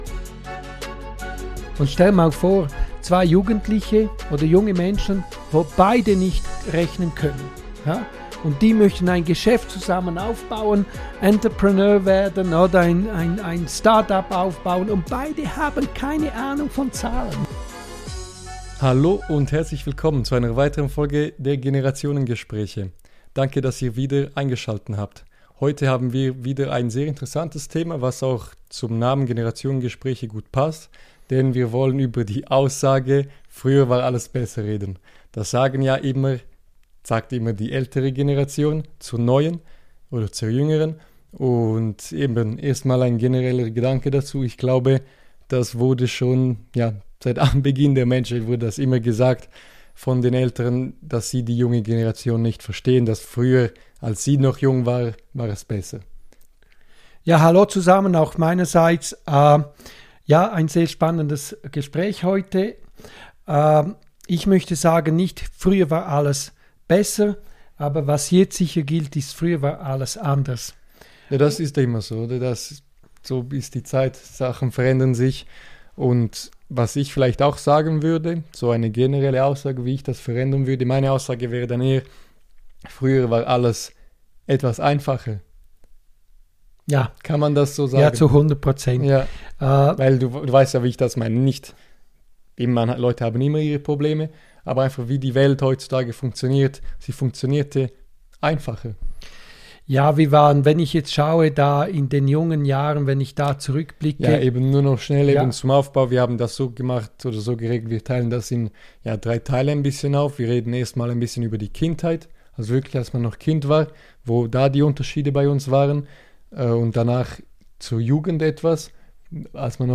Beschreibung vor 11 Monaten In dieser Folge sprechen Vater & Sohn darüber, ob früher wirklich alles besser war – oder nur anders. Wir nehmen euch mit durch drei Lebensphasen: die unbeschwerte Kindheit, die wilde Jugendzeit und den Übergang ins Erwachsenenleben mit all seiner Verantwortung.
Ein ehrlicher Generationentalk über Freiheit, Fehler, Erwartungen – und was wir heute manchmal vermissen.